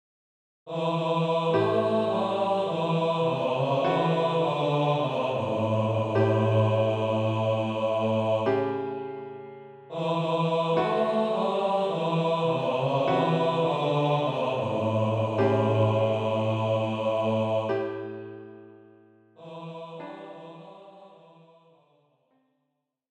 für Gesang, tiefe Stimme